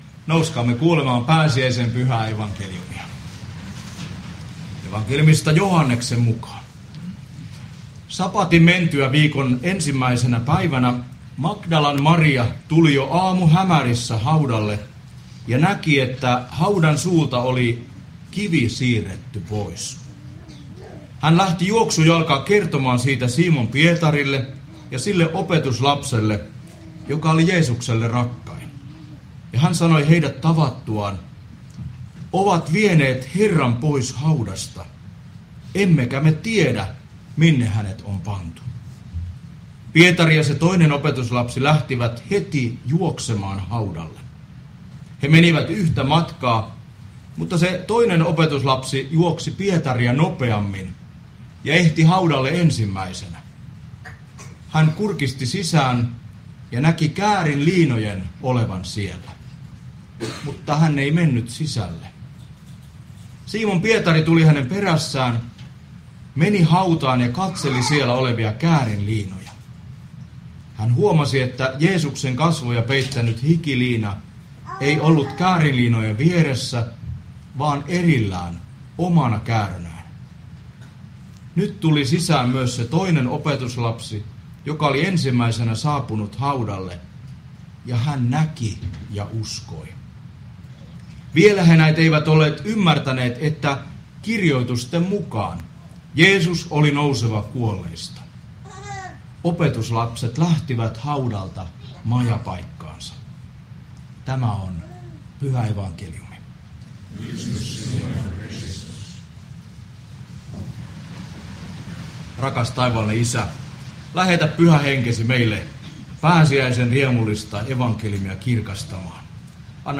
Pori